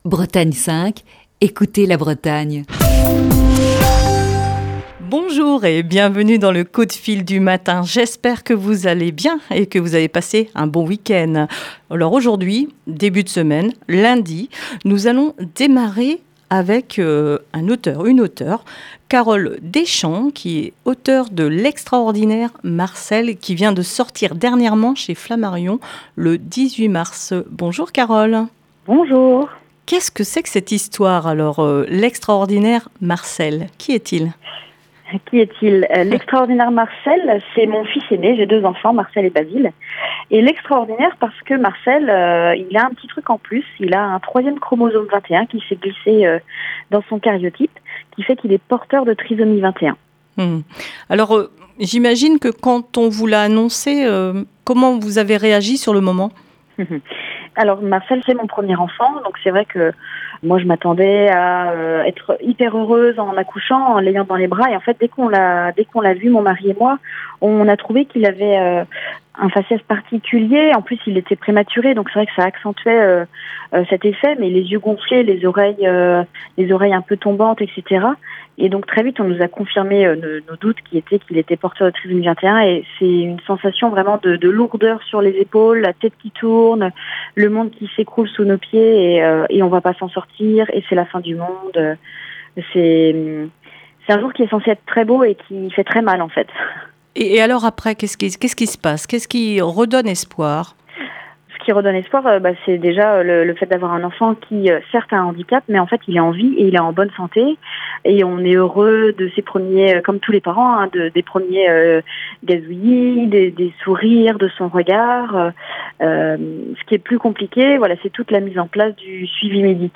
(Émission diffusée le 29 juin 2020).